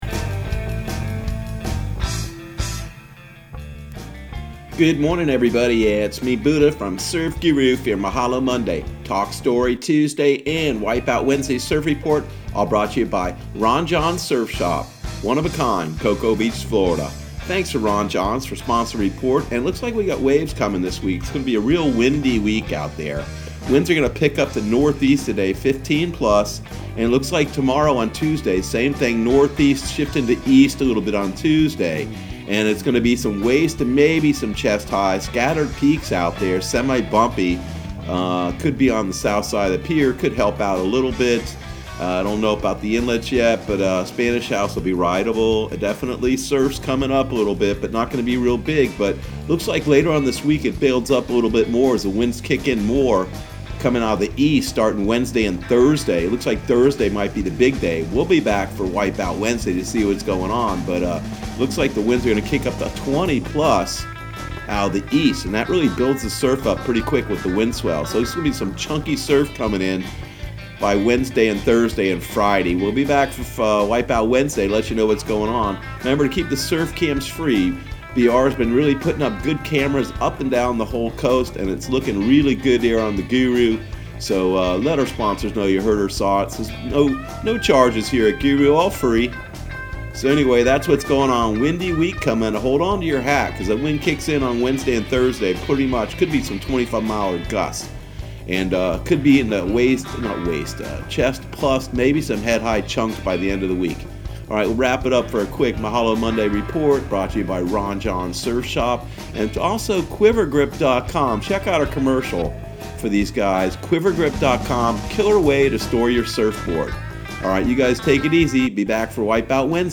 Surf Guru Surf Report and Forecast 05/11/2020 Audio surf report and surf forecast on May 11 for Central Florida and the Southeast.